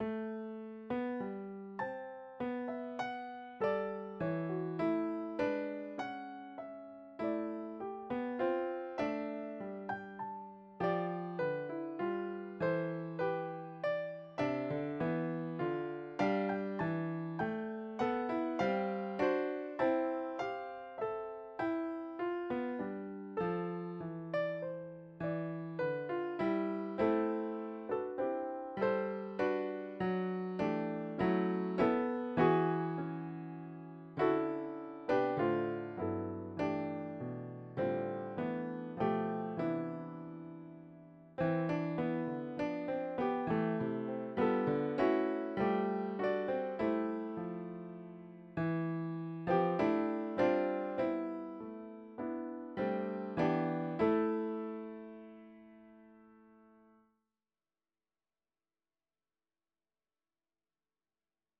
Recently I decided I wanted to get decent recordings of my thesis compositions, so I have been recording them using GarageBand.
Variation 1 is a retrograde inversion (the melody upside-down and backwards); variation 2 is a straightforward reharmonization; variation 3 is the melody in the form of a blues; variation 4 is an augmentation (one note of the melody is used in each measure of the variation's melody); variation 5 is a more interesting reharmonization.